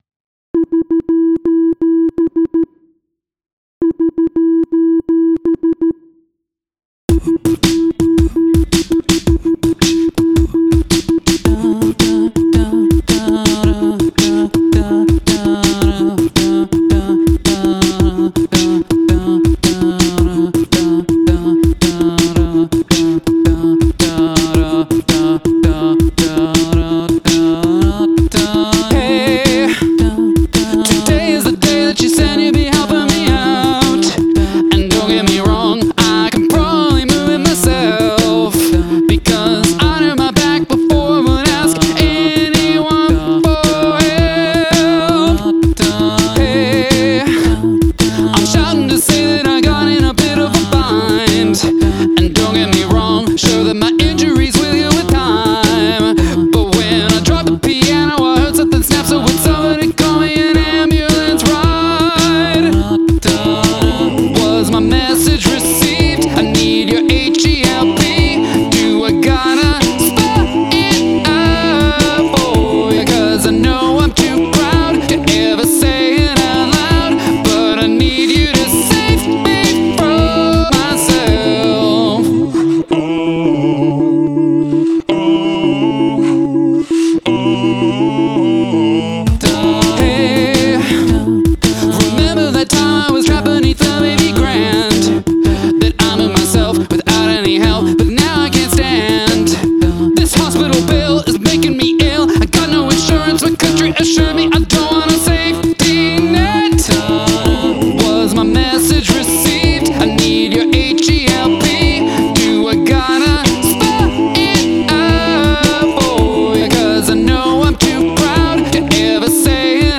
The lyrics are hilarious and the a Capella is fabulous.